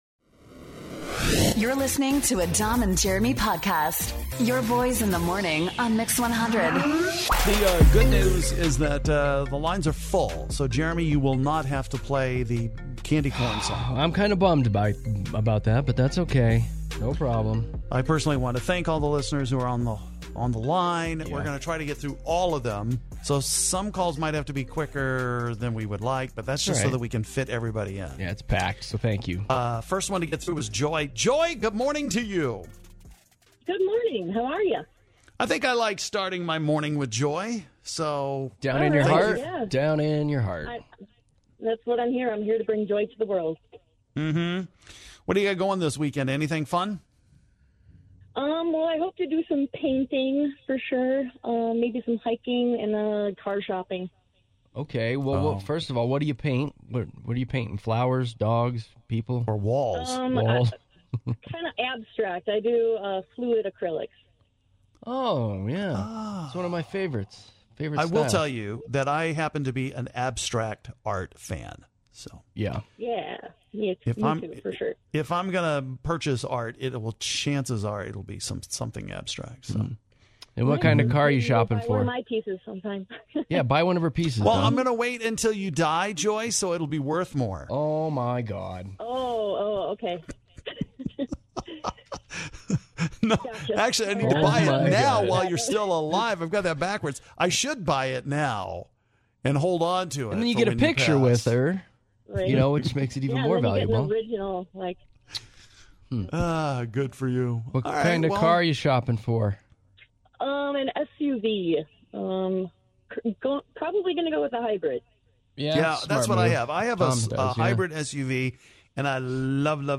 We have a great conversation with all you listeners here!